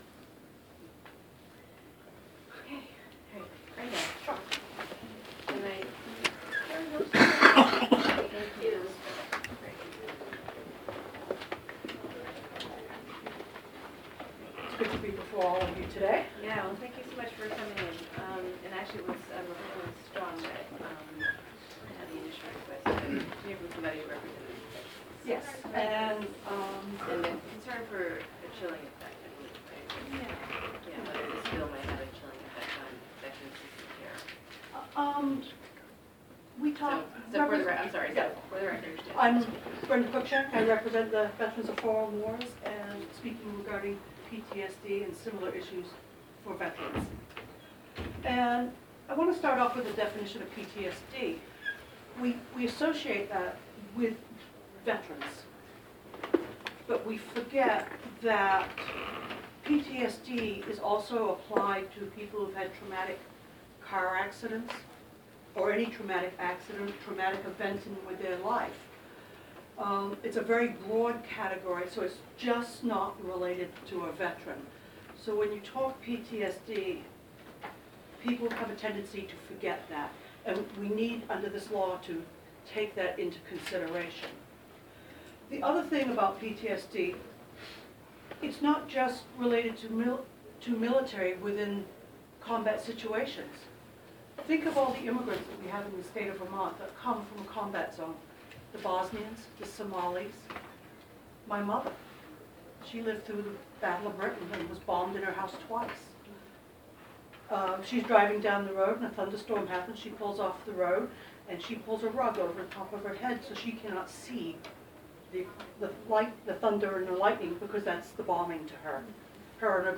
Her testimony was disjointed and it appeared that she was NOT trying to defend veterans rights but actually state that the bill doesn't go far enough in regards to PTSD, and she threw vets under the bus.